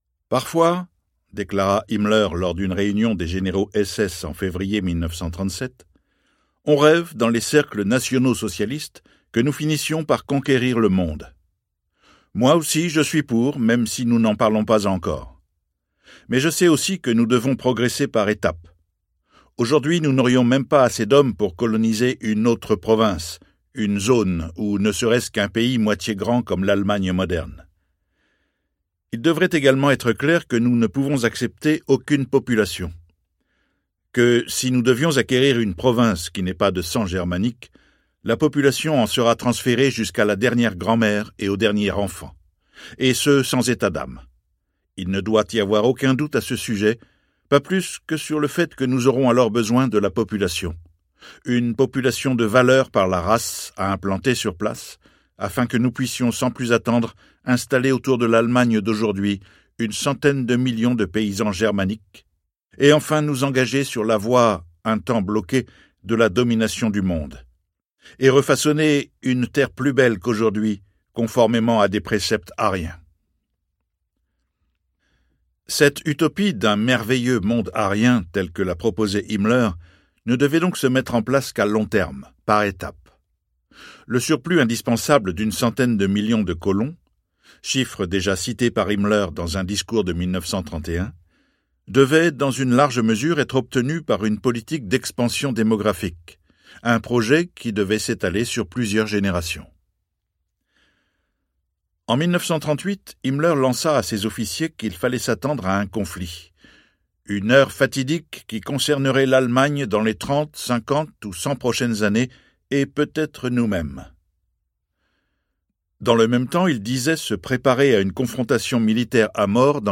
Livre audio Himmler Tome II. septembre 1939-mai 1945 de Peter Longerich | Sixtrid
Texte : Intégral